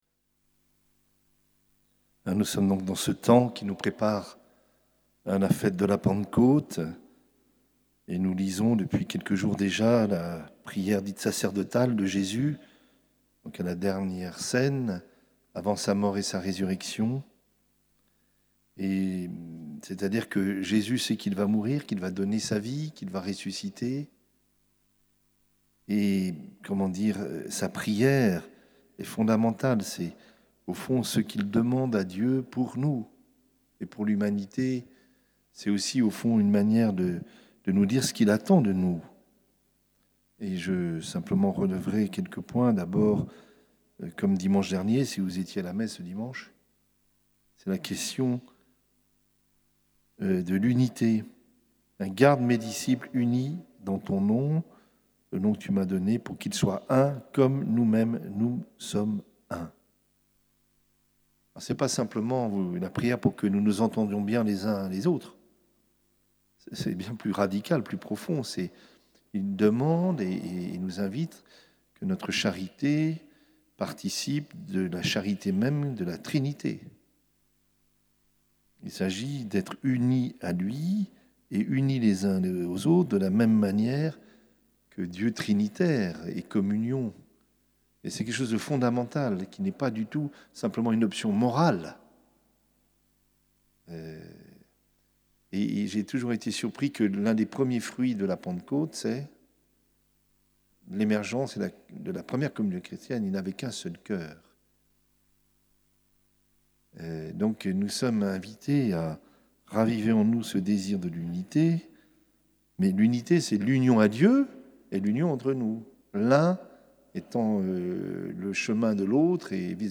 Homélie de Mgr Yves Le Saux